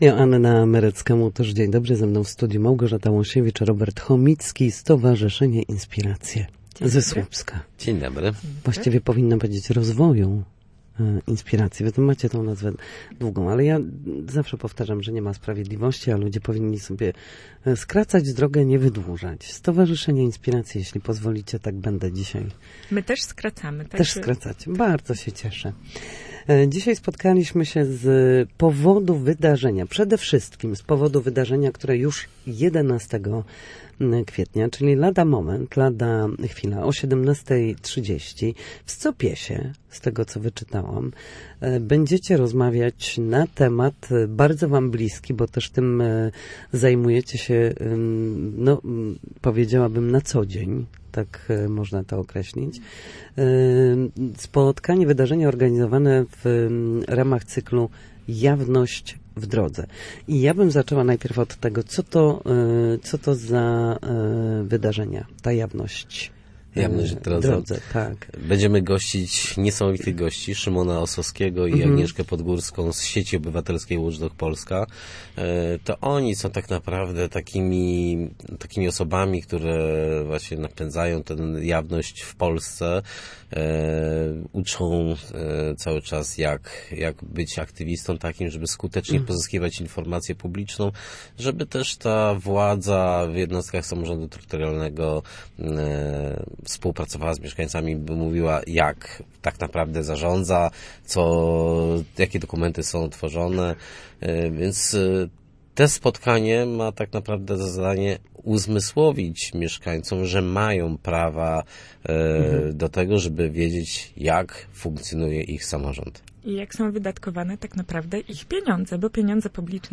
Na naszej antenie mówili o edukacji obywatelskiej, społecznej świadomości posiadanych praw, dostępie do informacji oraz spotkaniu, które odbędzie się w najbliższy piątek w Słupskim Centrum Organizacji Pozarządowych przy ul. Niedziałkowskiego 6.